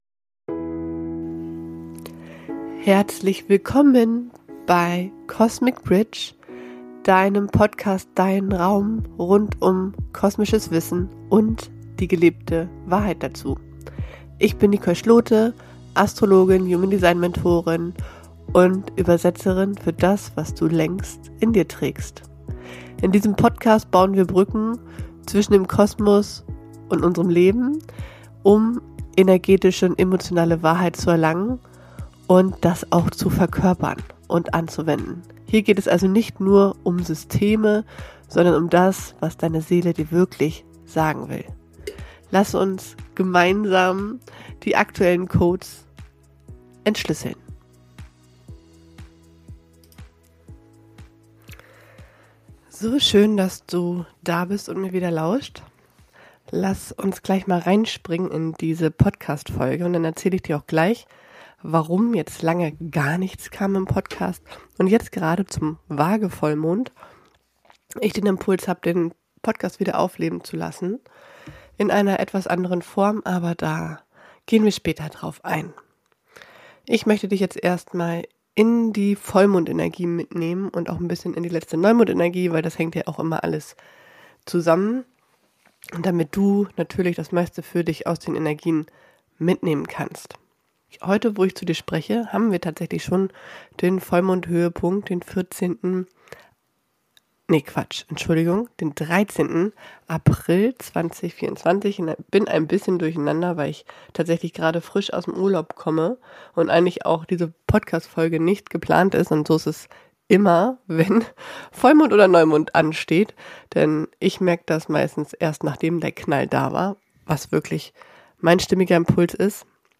In dieser Folge teile ich mit dir meinen ganz persönlichen Impuls zum Waage-Vollmond am 13. April 2025 – roh, echt, ungeschnitten. Es geht um Spiegel, Projektionen, das Ende alter Muster – und den Moment, in dem du dich entscheidest, wirklich du selbst zu sein.